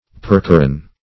Percheron \Per"che*ron\, n. [F.]